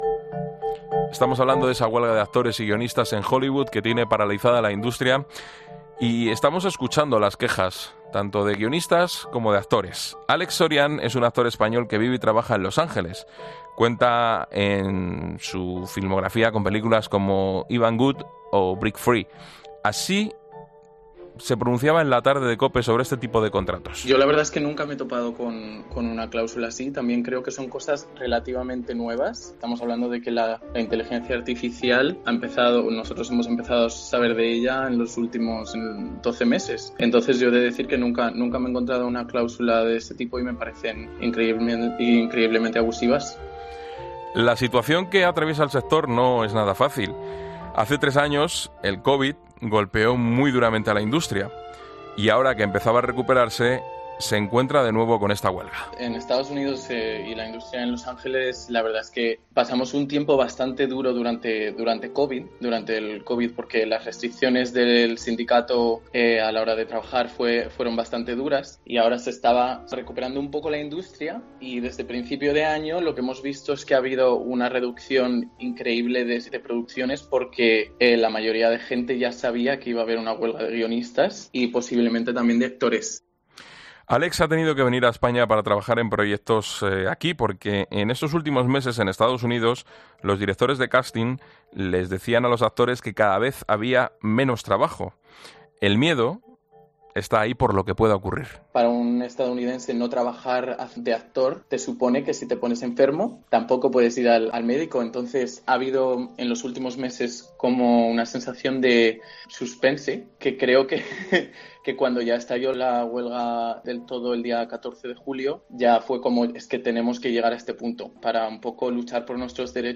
Un experto en inteligencia artificial explica como esta entrando esta tecnología al cine